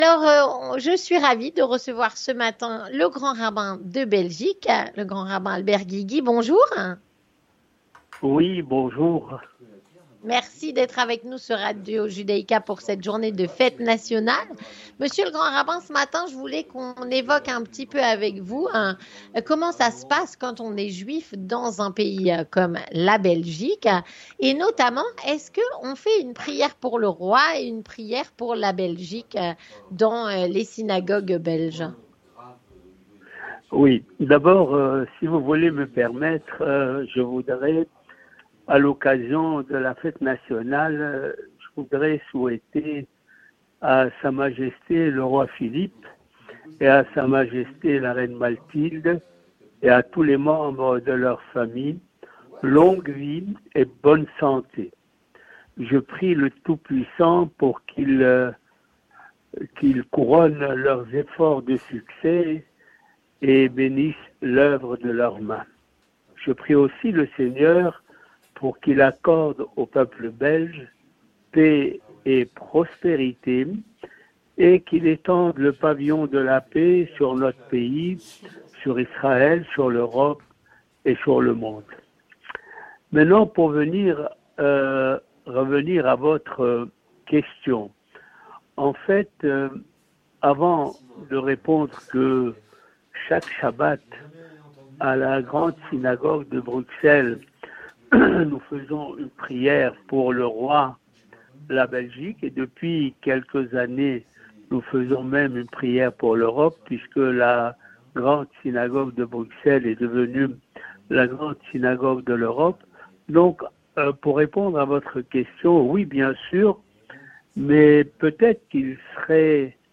L'interview communautaire - Le Grand rabbin de Belgique Albert Guigui, à l'occasion de la Fête nationale